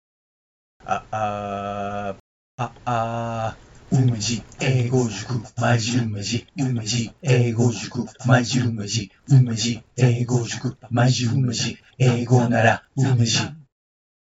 ちなみに、ジングルとは、短い音楽やセリフで印象に残すための音声のことです。
11月30日に制作を思いつき、自宅で録音・編集を行い、本日(12月16日)、ついにお披露目です。